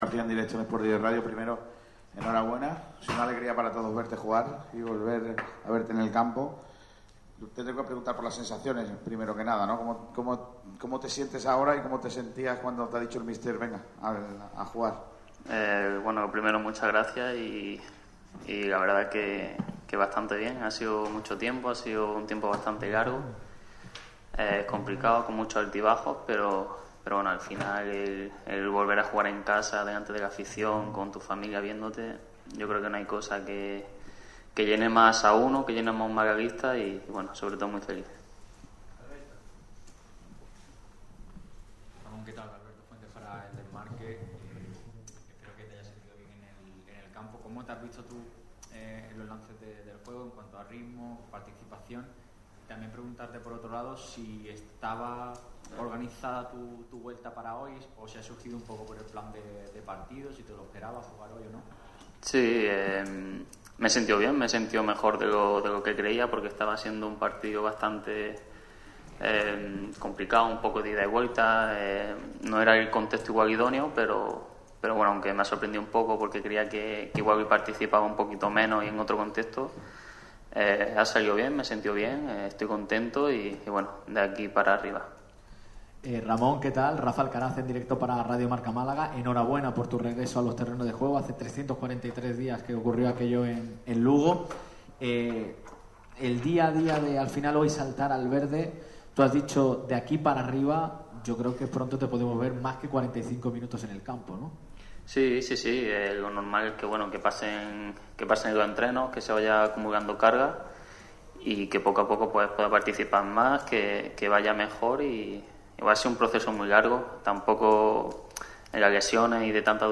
Con gran emoción en sus palabras, el jugador malaguista valora su regreso.